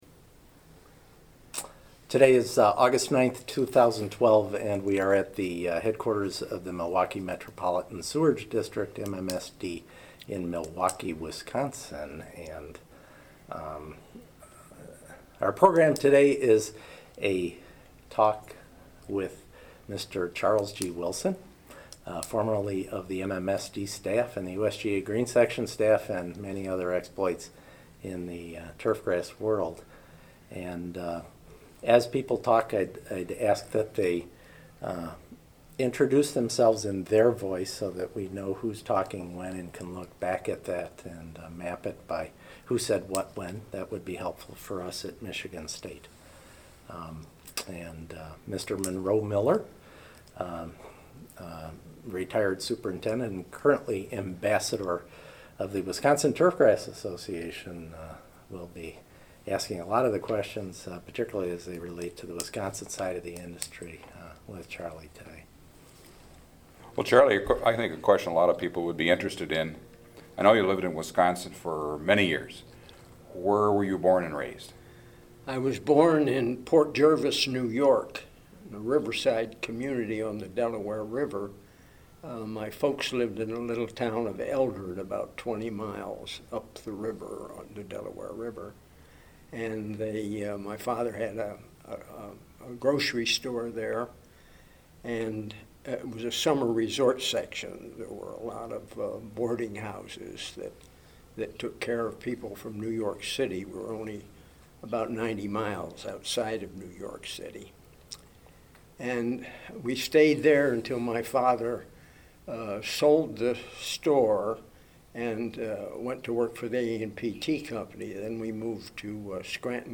Green Section O.J. Noer Research Foundation Material Type Sound recordings Language English Extent 01:39:00 Venue Note Interviewed at the headquarter of Milwaukee Metropolitan Sewage District, Milwaukee, Wis., Aug. 9, 2012.